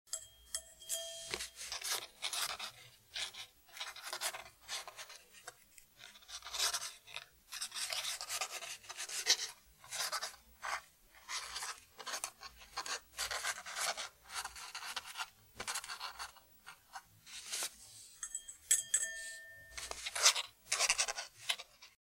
Звук чернильницы и скрежет пера